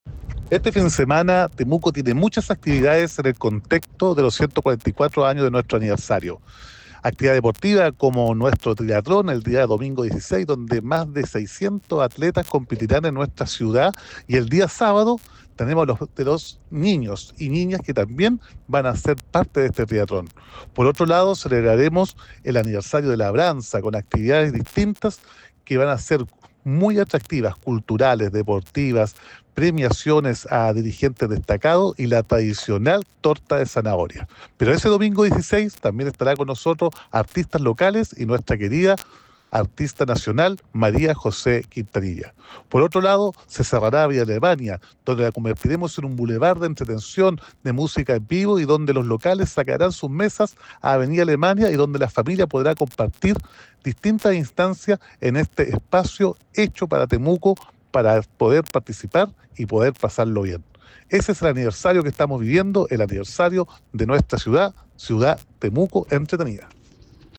Roberto-Neira-alcalde-de-Temuco.mp3